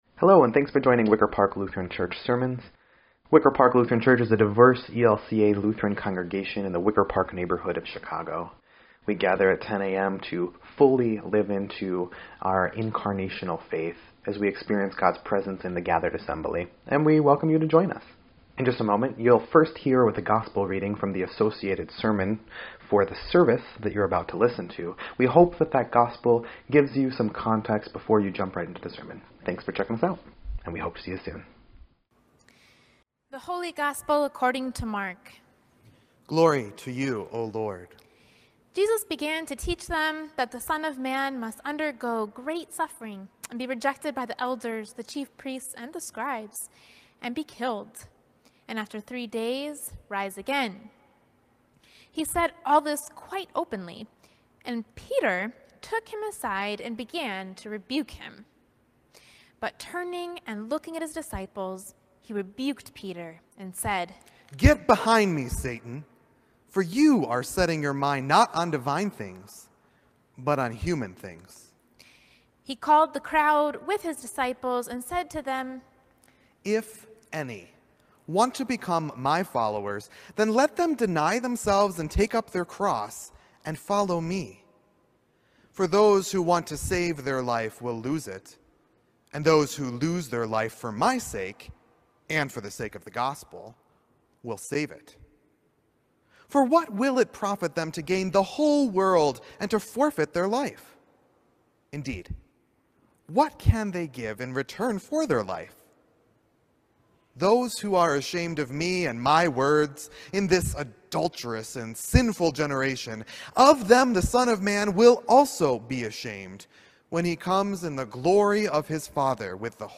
2.28.21-Sermon_EDIT.mp3